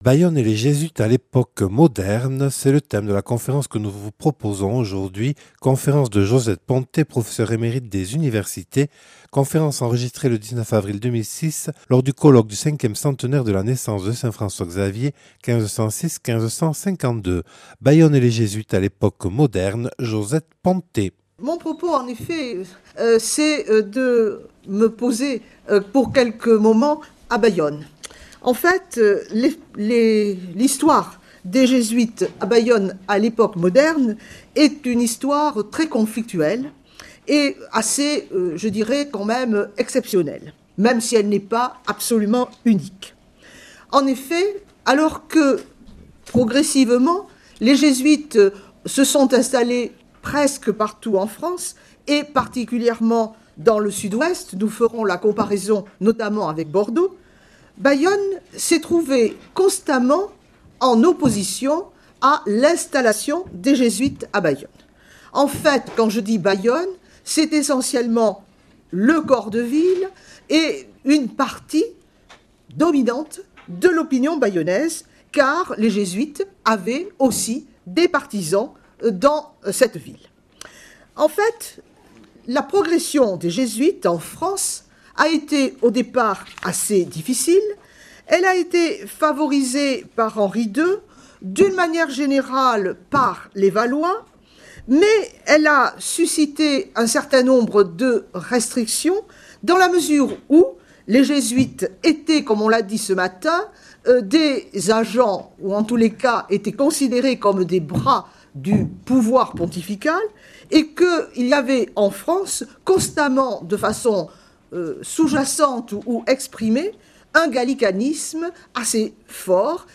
(Enregistré le 19/04/2006 à Bayonne lors du colloque du 5ème centenaire de la naissance de saint François-Xavier (1506-1552).